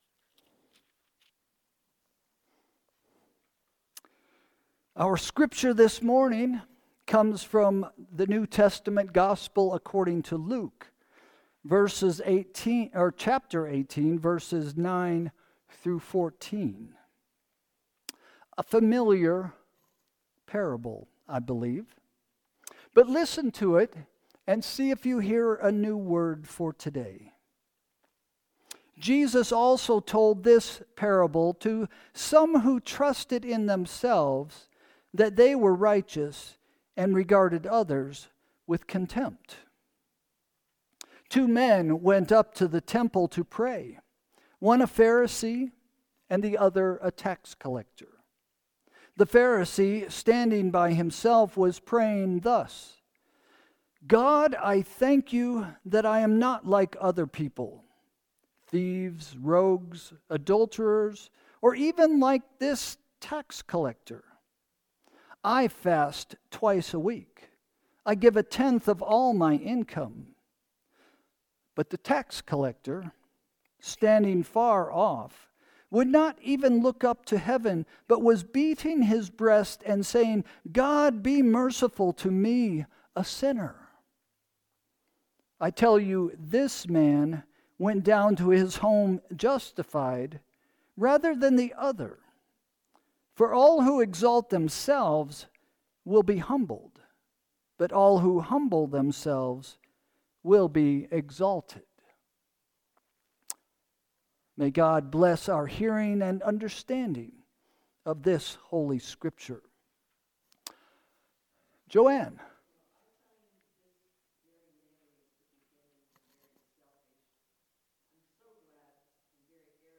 Sermon – April 27, 2025 – “Room to Grow” – First Christian Church